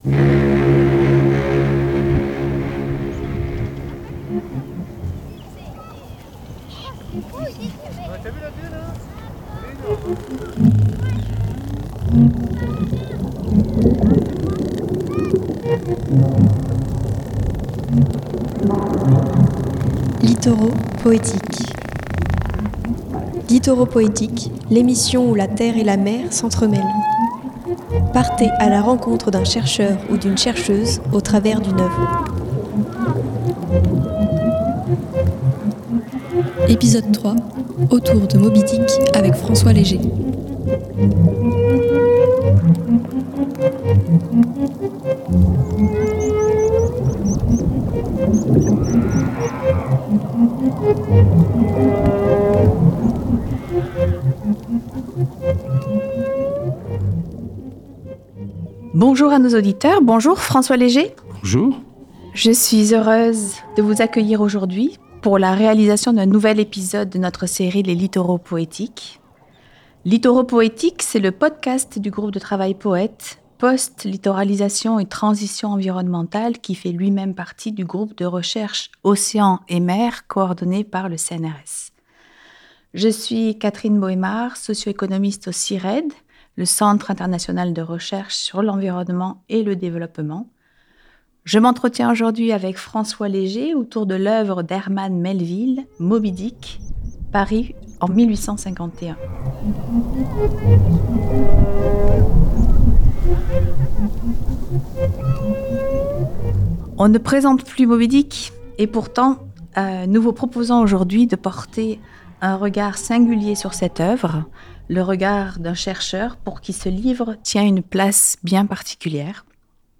Littoraux poétiques est une émission qui entremêle la mer et la terre, la création et la recherche. Un chercheur ou une chercheuse raconte son parcours au travers d’une œuvre qui l’inspire et accompagne ses recherches.
Interview